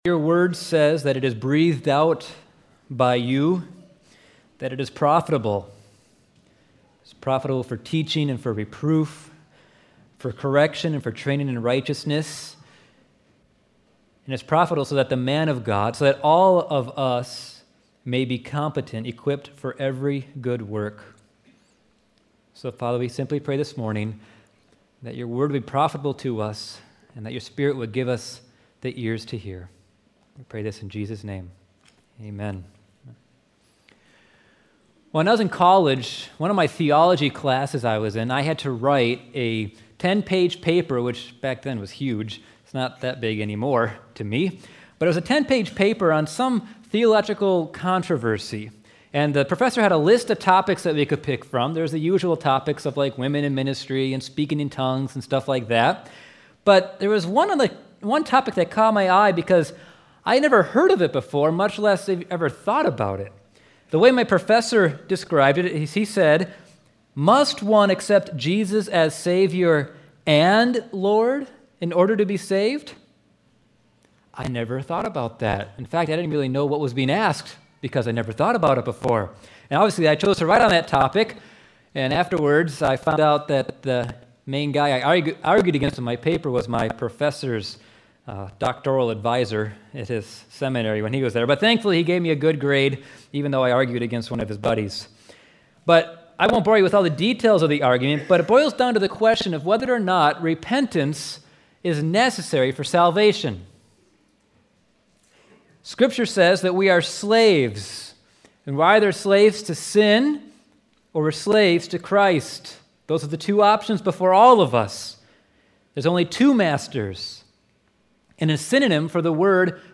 Sermons | Rothbury Community Church